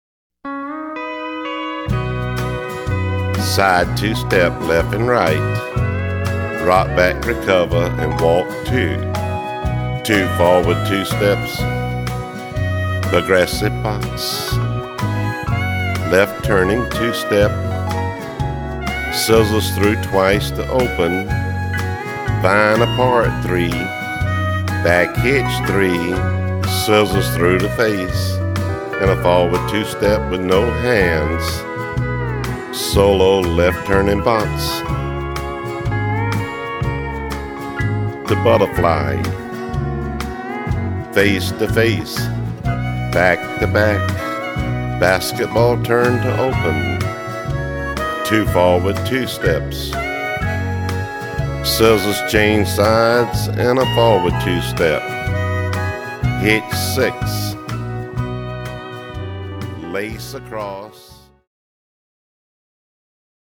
Cued Sample
Two Step